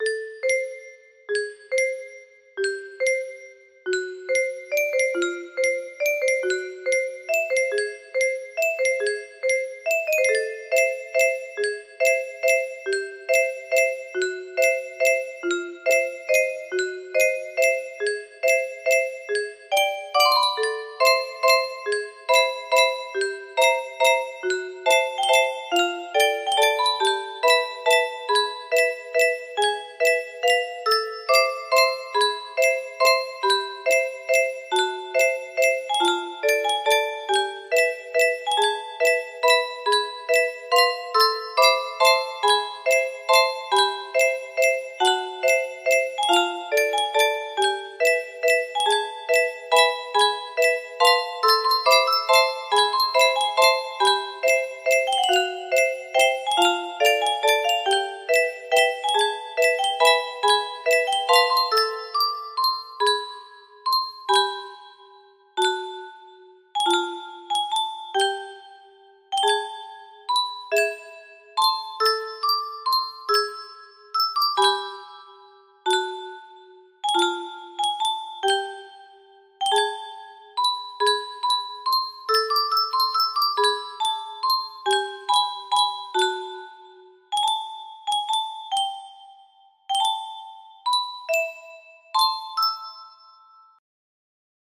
A Sorrowful night. music box melody